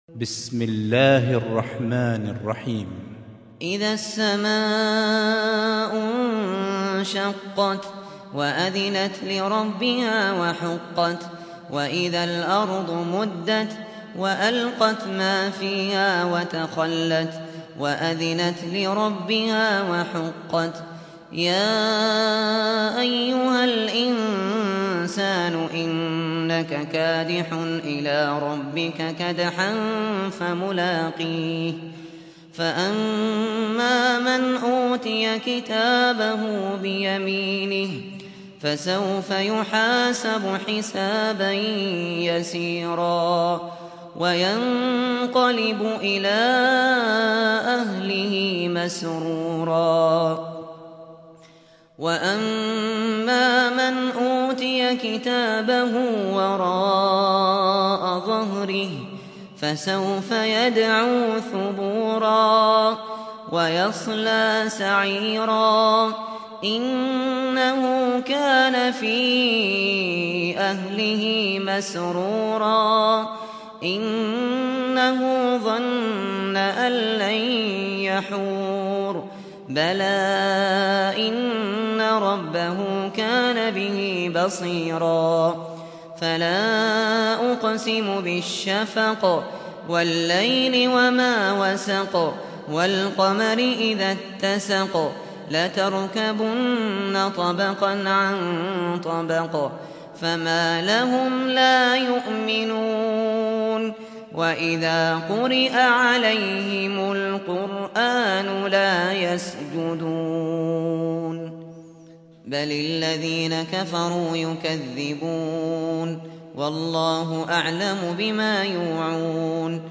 سُورَةُ الانشِقَاقِ بصوت الشيخ ابو بكر الشاطري